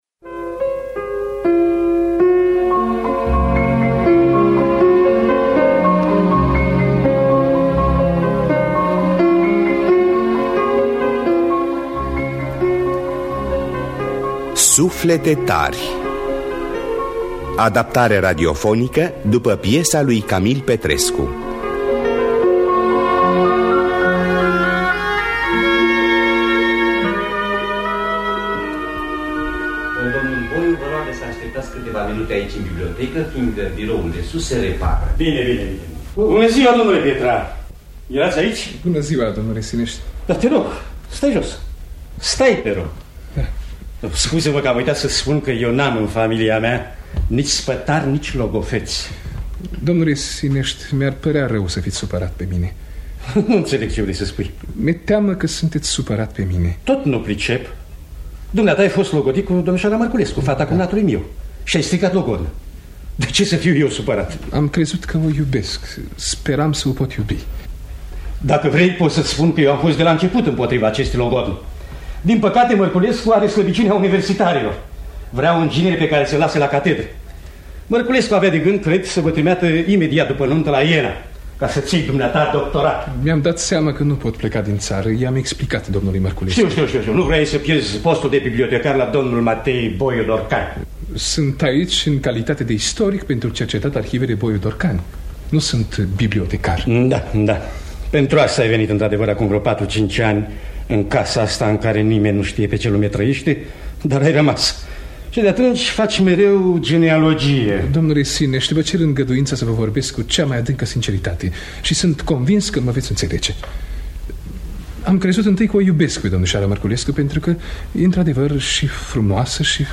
Suflete tari de Camil Petrescu – Teatru Radiofonic Online